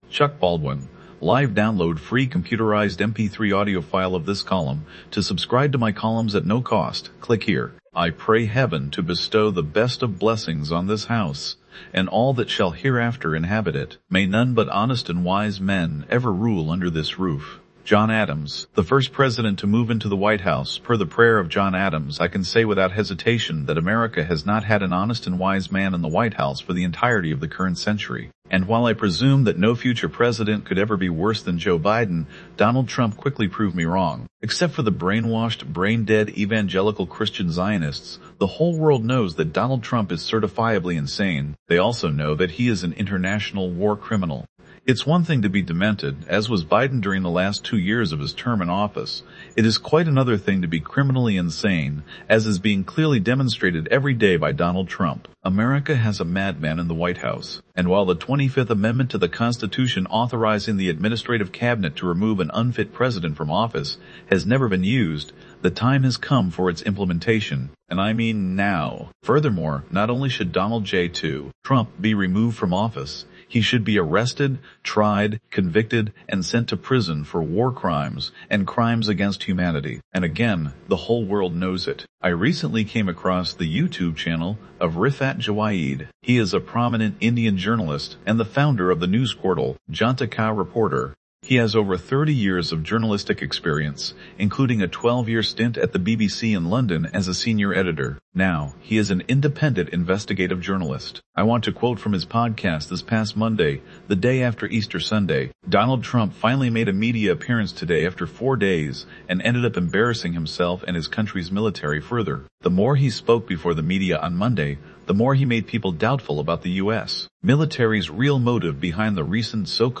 Download free computerized mp3 audio file of this column